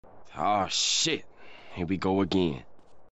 gta san andreas ah shit here we go again sound effects